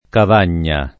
[ kav # n’n’a ]